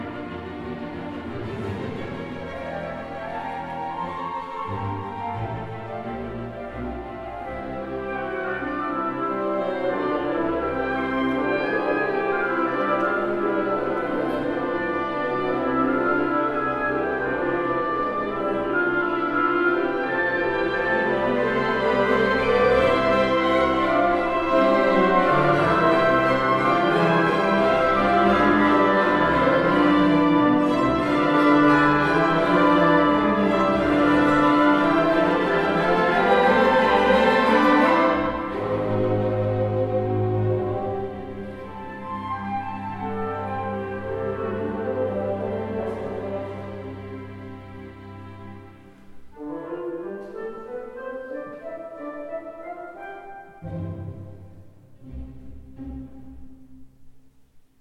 Es spielt der Orchesterverein Einsiedeln, aufgenommen am 8. November 2015 in der Jugendkirche Einsiedeln.